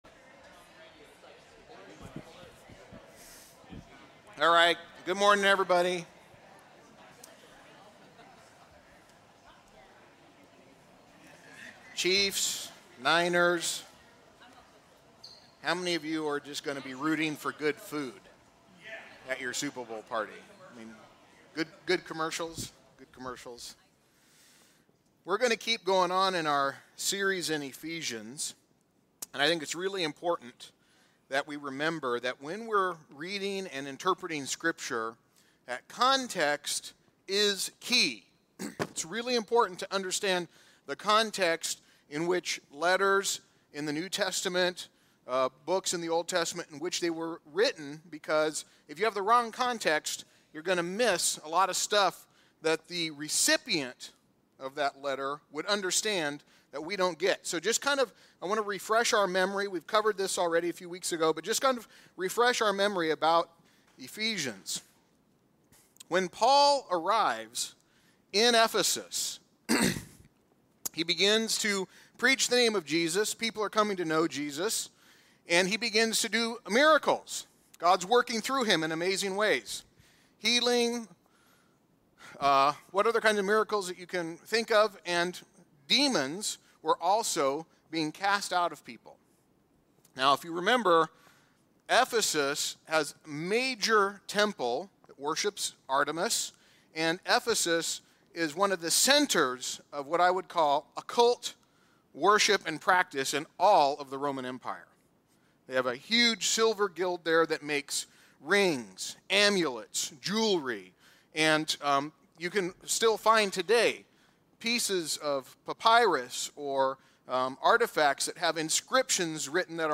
Part 5 of our sermon series from the book of Ephesians.